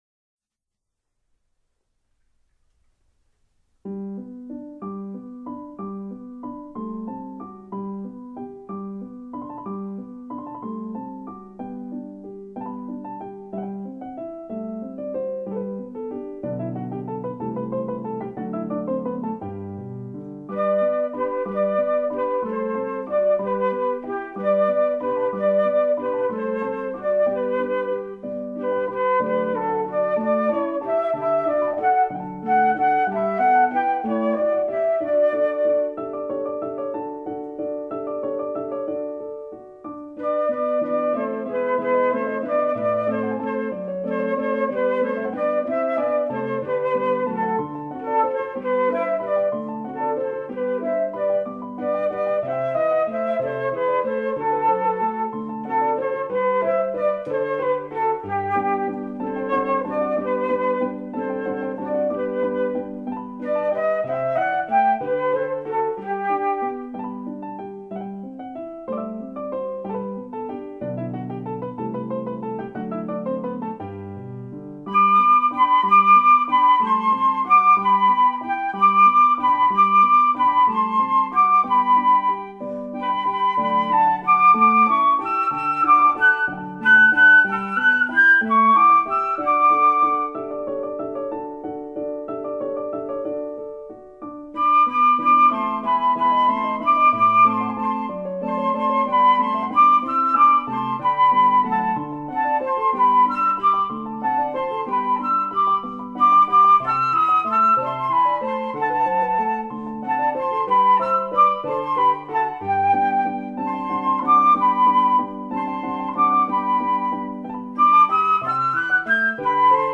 A.A. 24/25 Canto Corale
Ricevete-SOP-Flauto-Mozart.mp3